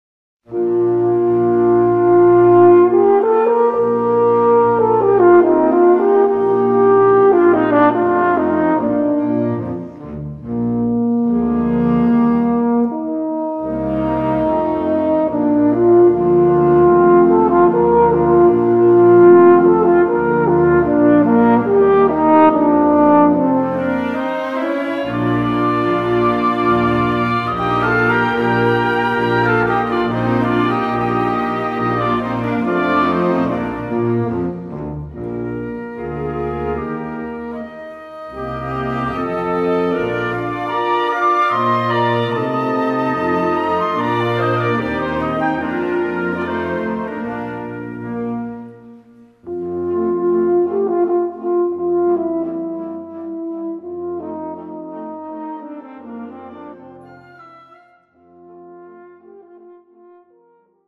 Gattung: Horn in F Solo
Besetzung: Blasorchester